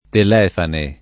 [ tel $ fane ]